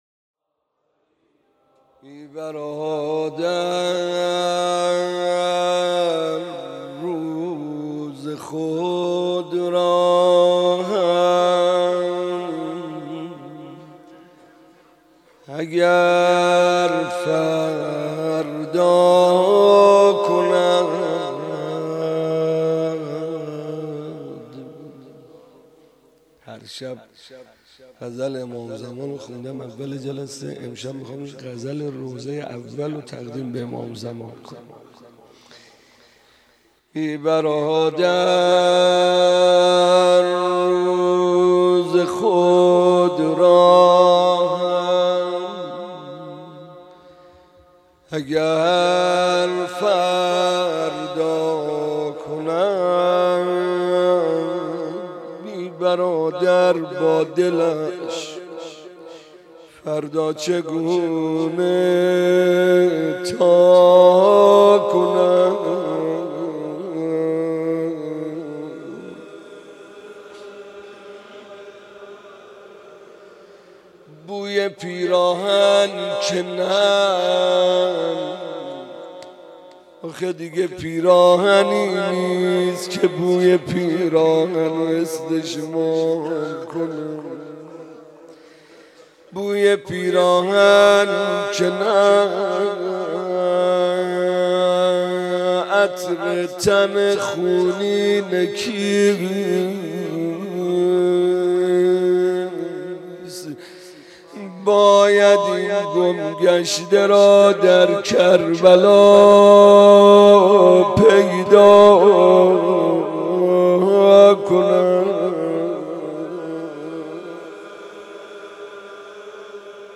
روضه اسارت و حضرت زینب (س)